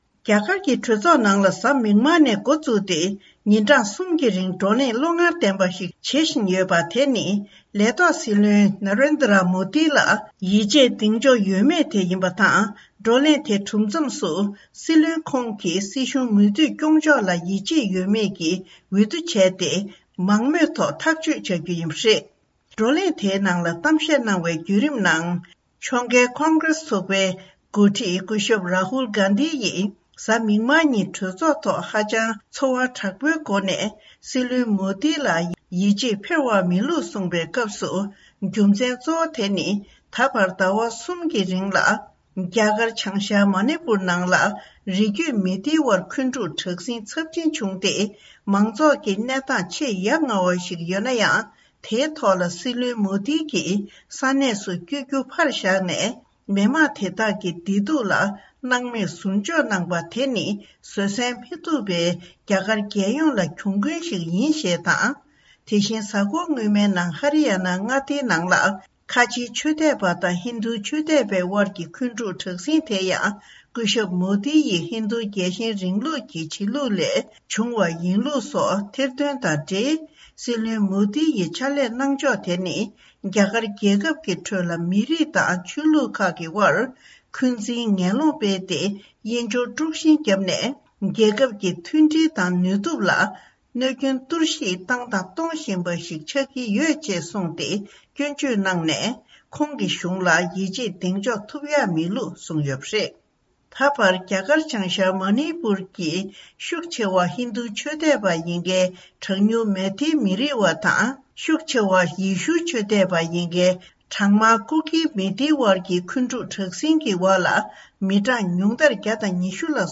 སྙན་སྒྲོན་ཞུ་ཡི་རེད།